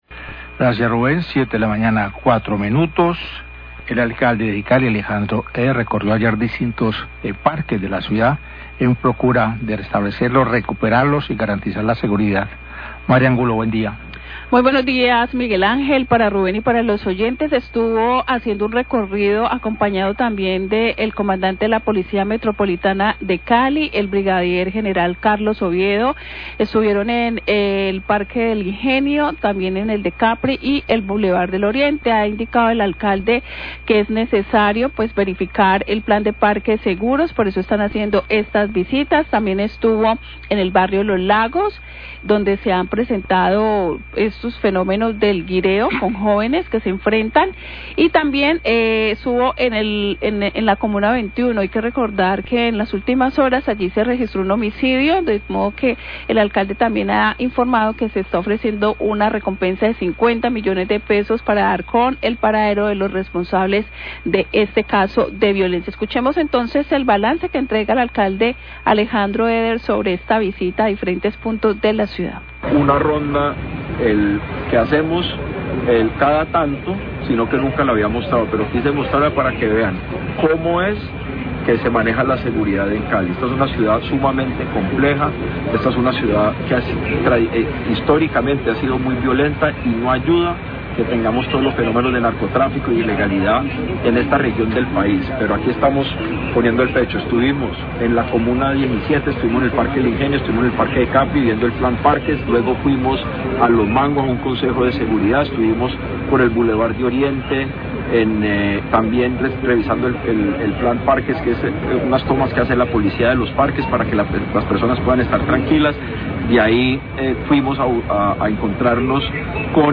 NOTICIERO RELÁMPAGO